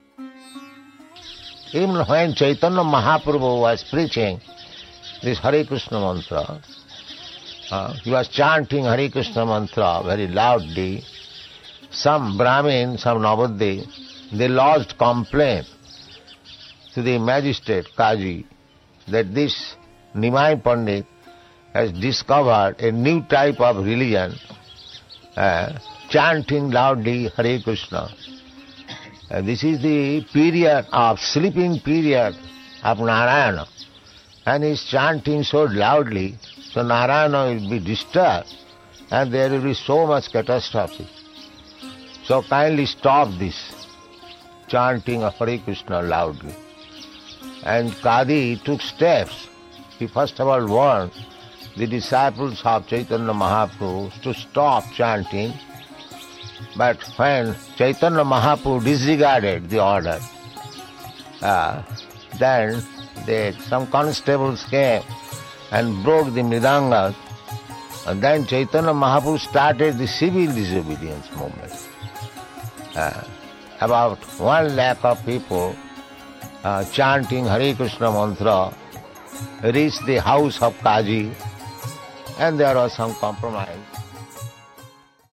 (721114 - Lecture NOD - Vrndavana)